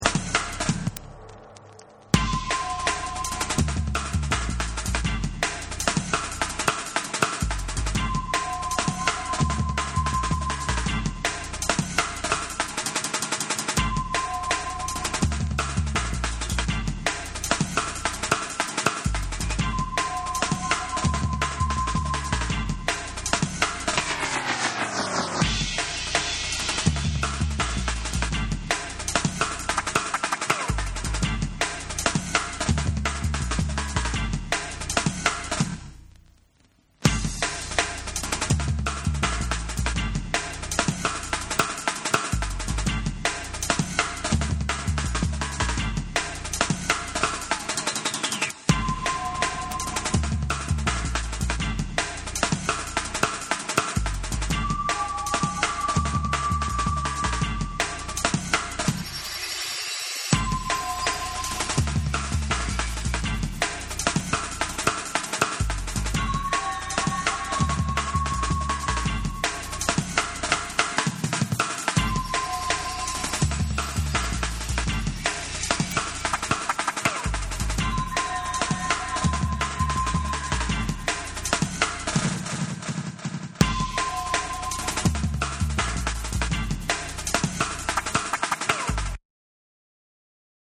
シネマティックで壮大な展開のSAMPLE1をはじめ、PHOTEKの諸作にも似たドラムンベース等も収録しています。
Drum n' Bass / AMBIENT